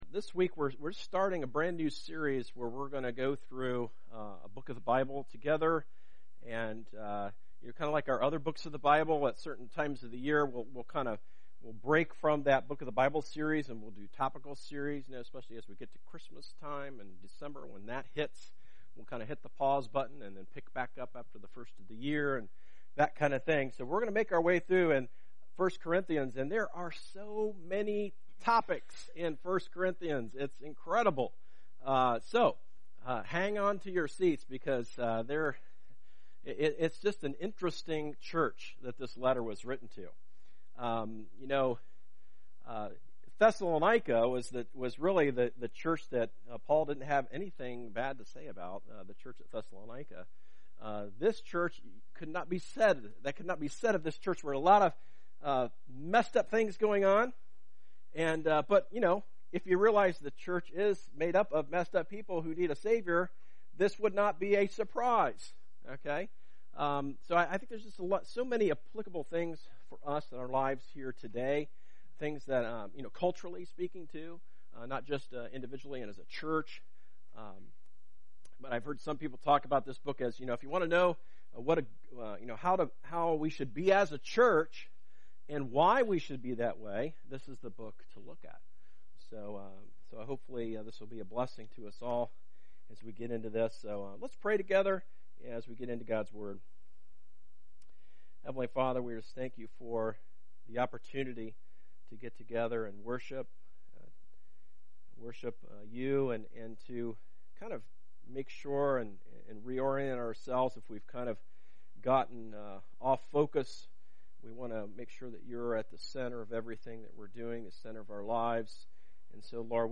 A message from the series "Church Matters."